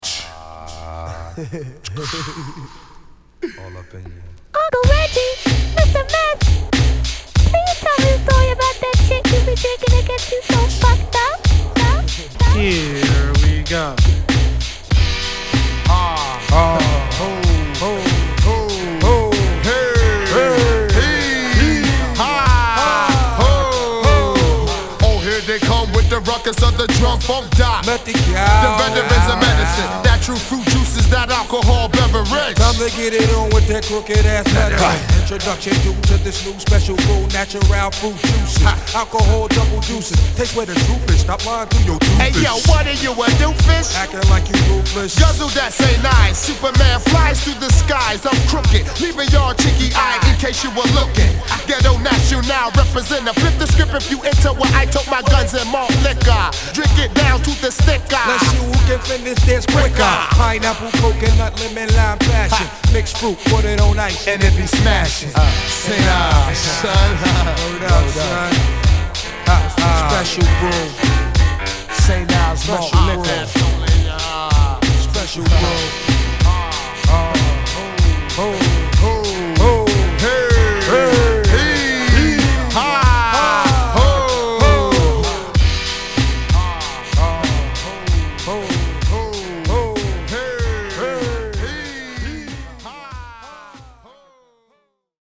ホーム HIP HOP 90's 12' & LP R